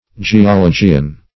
Geologian \Ge`o*lo"gi*an\